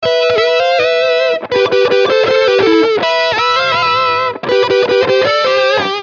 描述：电吉他的和声主导。这就是摇滚的男孩和女孩
Tag: 80 bpm Rock Loops Guitar Electric Loops 1.01 MB wav Key : Unknown